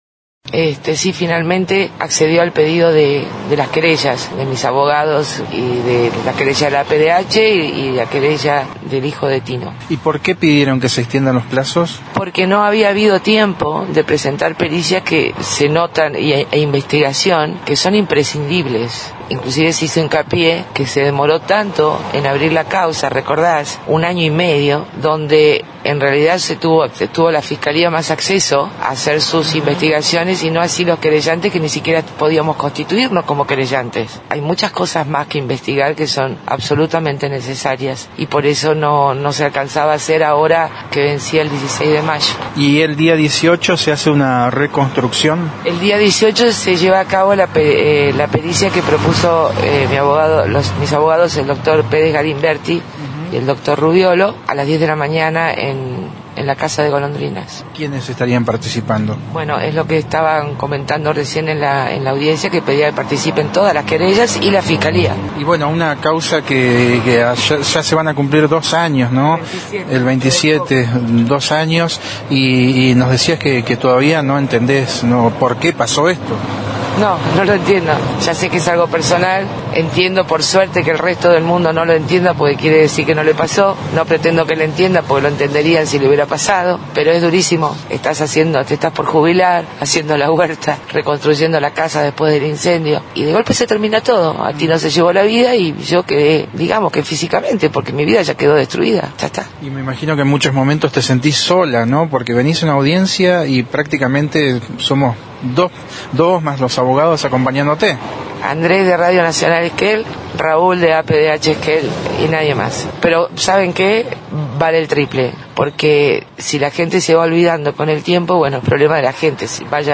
Luego de finalizada la audiencia, el móvil de Radio Nacional Esquel (único medio cubriendo esta audiencia)